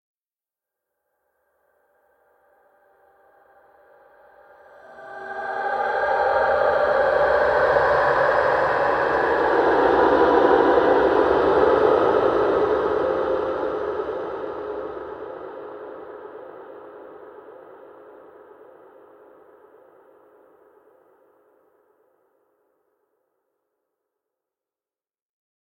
Призрачная атмосфера
prizrachnaya_atmosfera_2a0.mp3